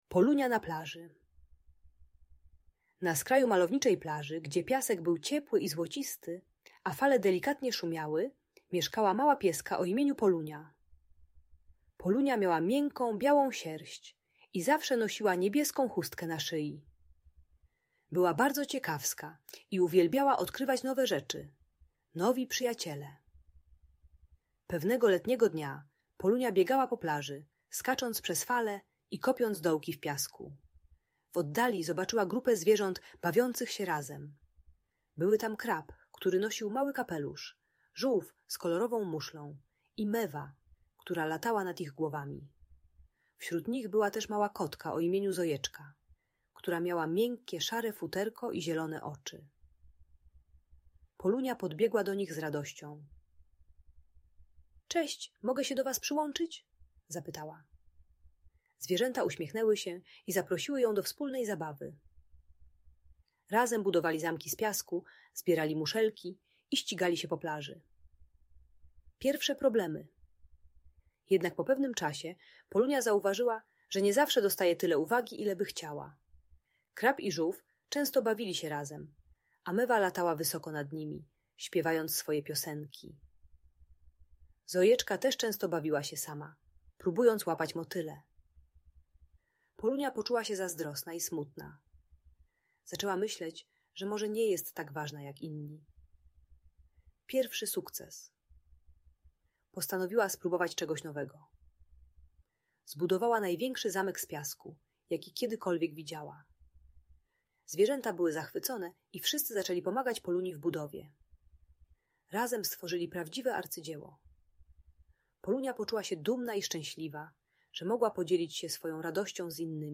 Opowieść o Polunii: Przyjaźń i Współpraca na Plaży - Audiobajka